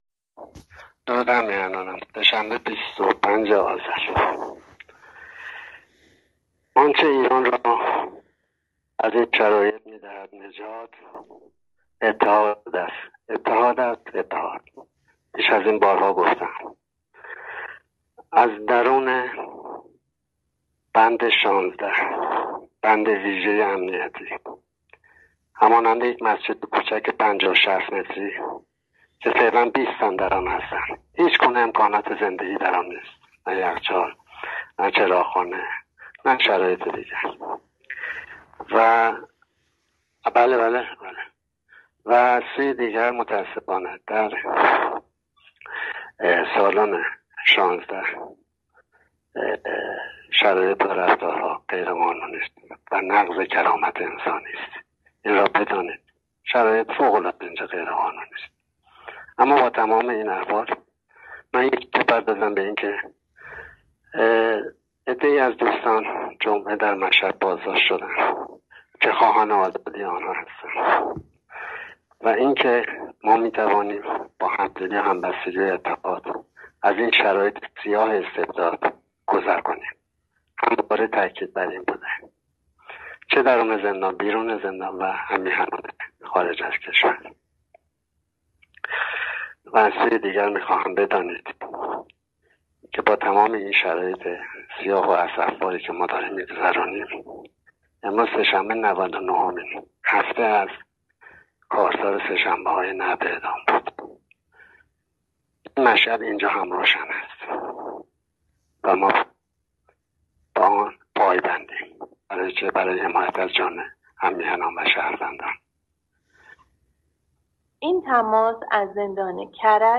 زندانی سیاسی محبوس در زندان مرکزی کرج
پیامی کوتاه از زندان مرکزی کرج